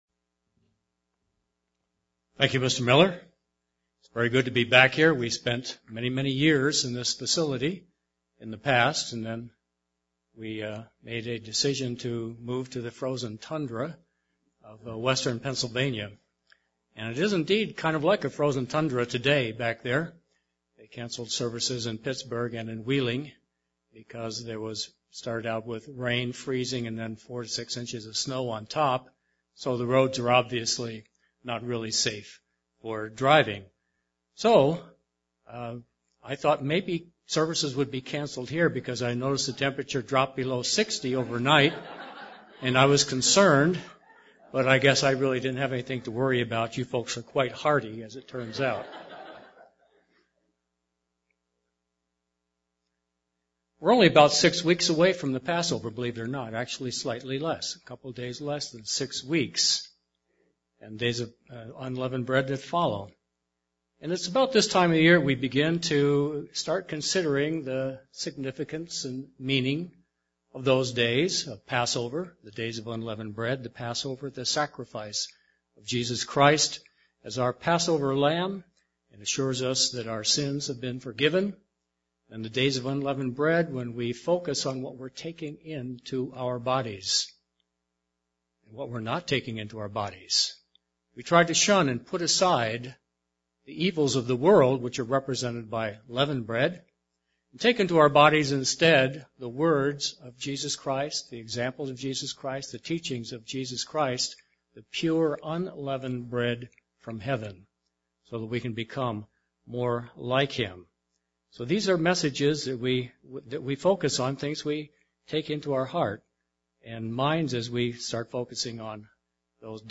Given in San Diego, CA
UCG Sermon Studying the bible?